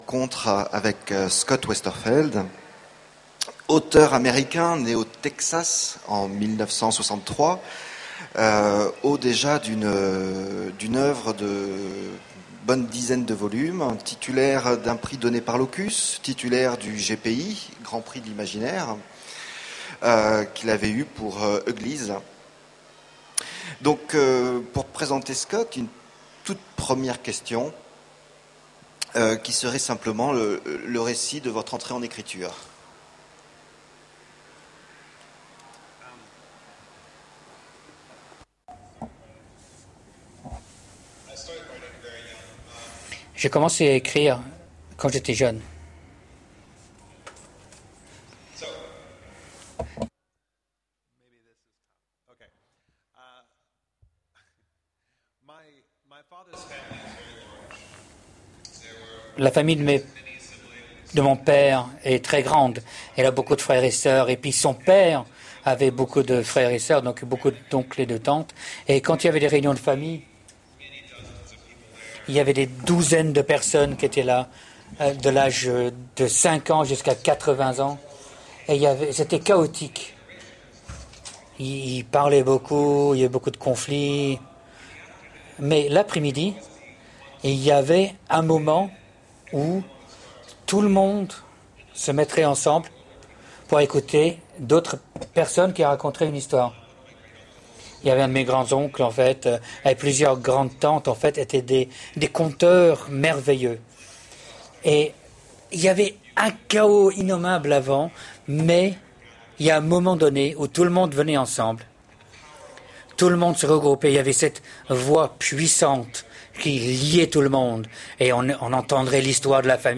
Utopiales 2010 : Conférence rencontre avec Scott Westerfeld le dimanche
Voici l'enregistrement de la rencontre avec Scott Westerfeld aux Utopiales 2010 le dimanche 14 novembre.